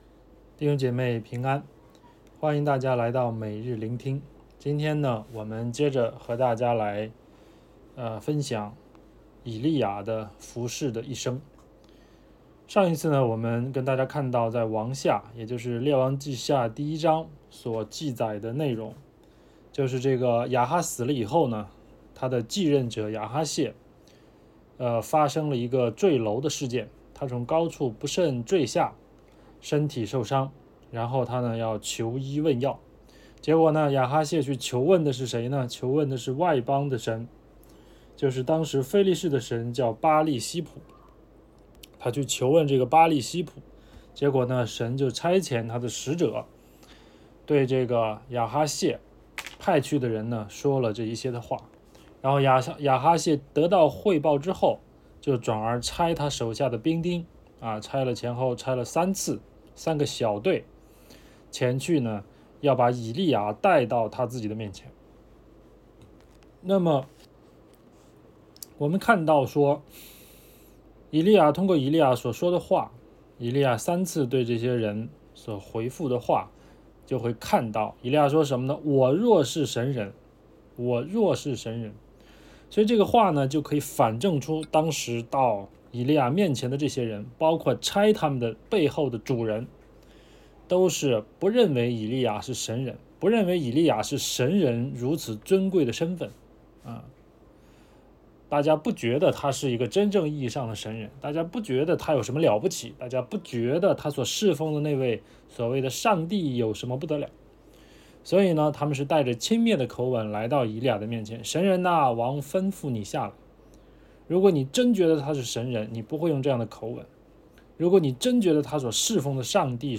证道